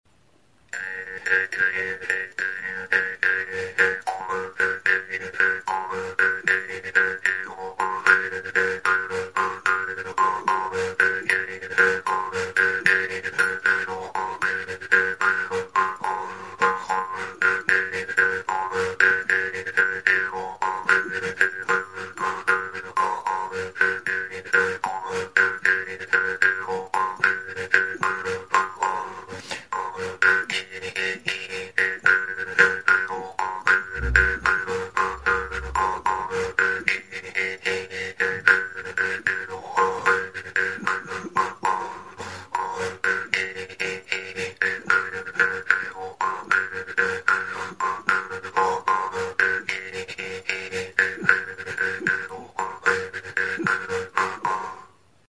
Idiófonos -> Punteados / flexible -> Sin caja de resonancia
Grabado con este instrumento.
EUROPA -> EUSKAL HERRIA
Giltza forma duen metalezko uztaia da. Altzairuzko mihi luzea du erdi-erdian, hatzarekin astintzerakoan libre bibratzen duena.